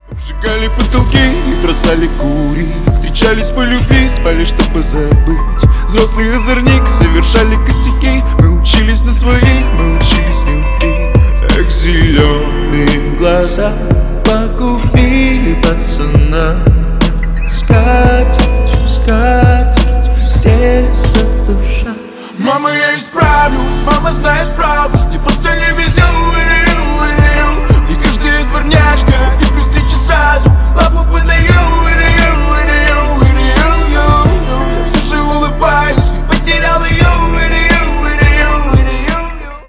Поп Музыка # Рэп и Хип Хоп